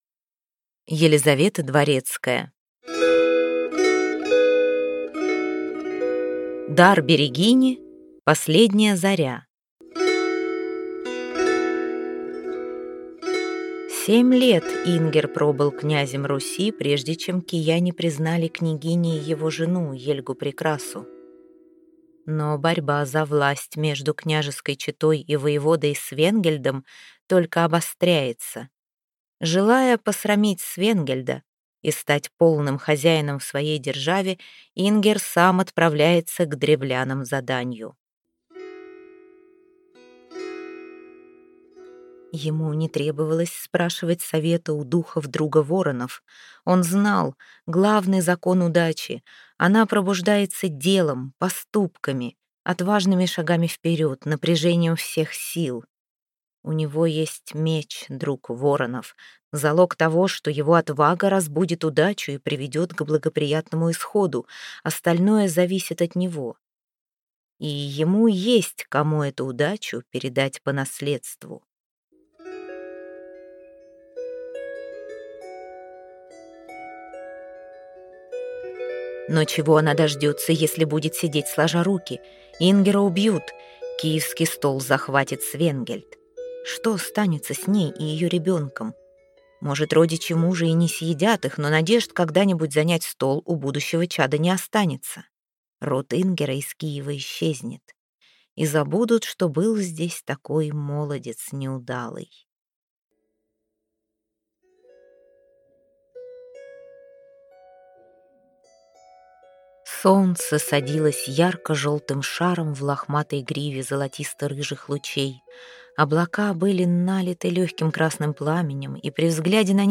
Аудиокнига Дар берегини. Последняя заря | Библиотека аудиокниг